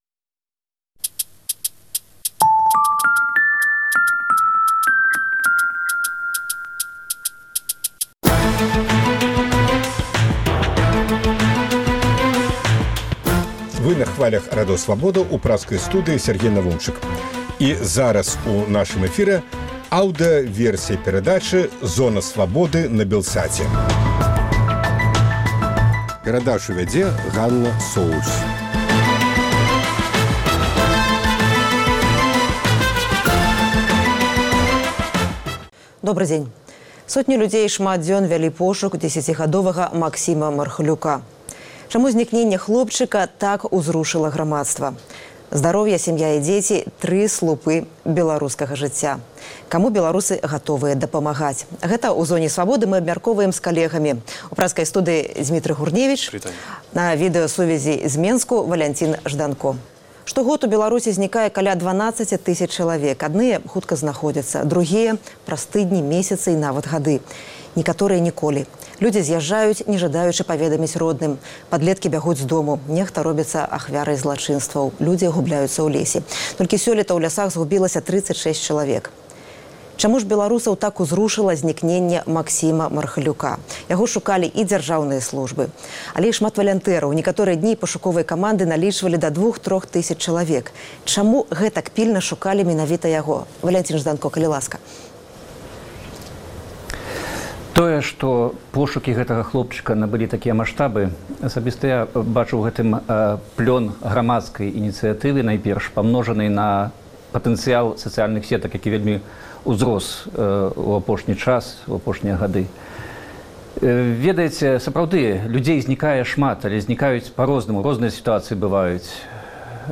Аўдыёвэрсія перадачы "Зона Свабоды" на тэлеканале Белсат.